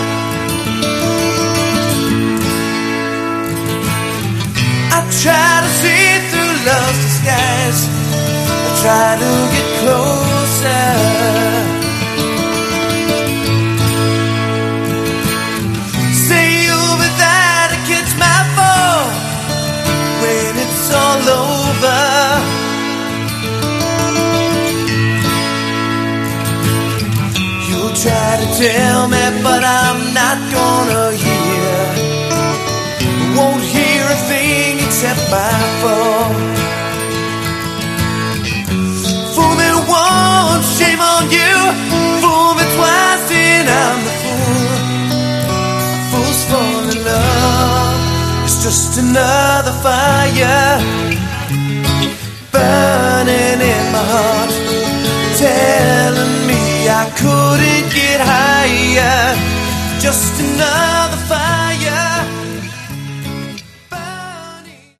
Category: Hard Rock
lead vocals
guitar, keyboards
bass
drums